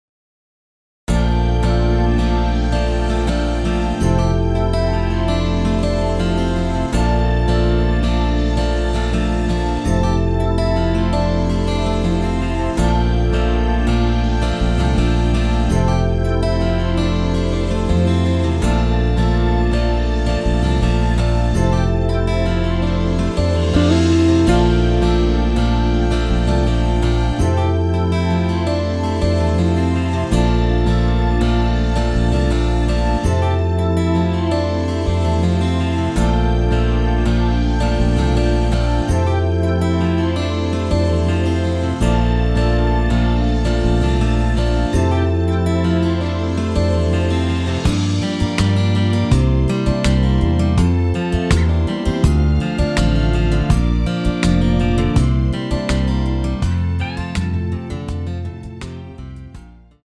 엔딩이 페이드 아웃이라 라이브 하시기 좋게 엔딩을 만들어 놓았습니다.